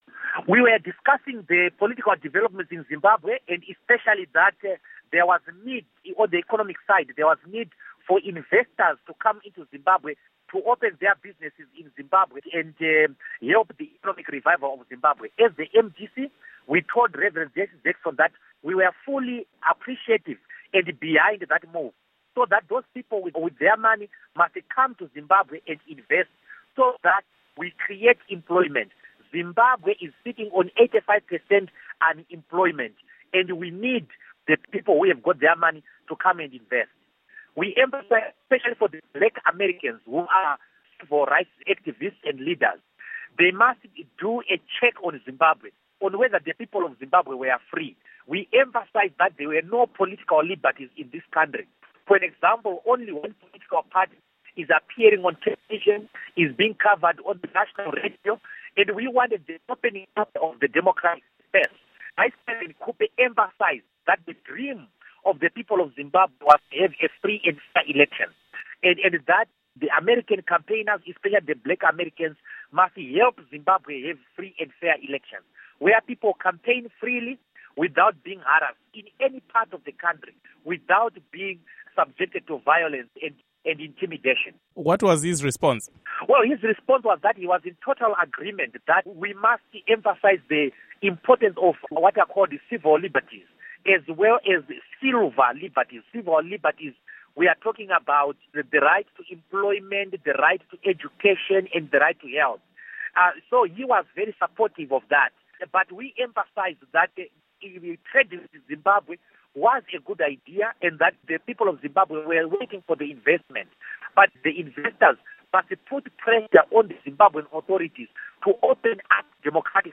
Interview With Bruce Wharton